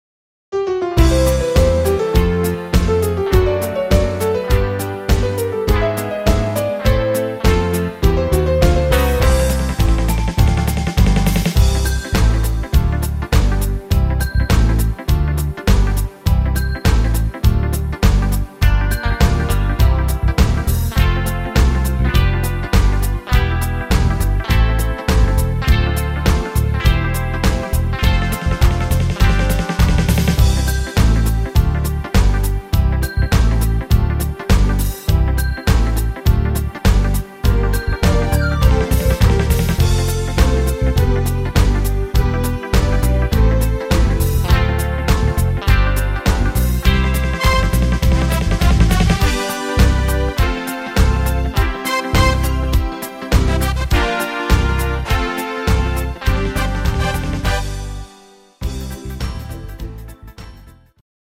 Rhythmus  Medium Shuffle